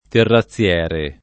[ terra ZZL$ re ]